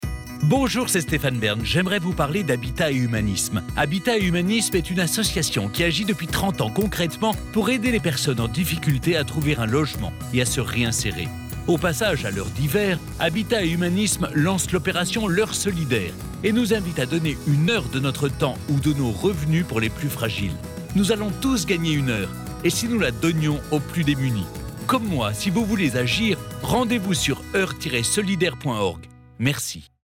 Un partenariat avec le groupe M6/RTL et la diffusion gracieuse de spots télé et radio, avec la voix de Stéphane Bern !